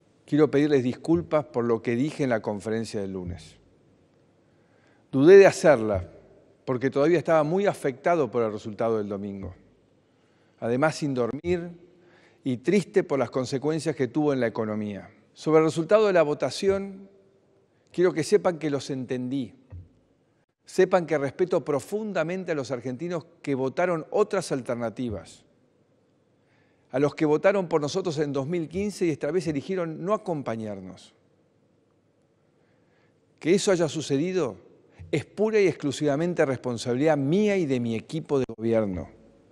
A los días envió otro mensaje, esta vez sin preguntas y domesticado…
AUDIO-2-MACRI-DISCULPAS.m4a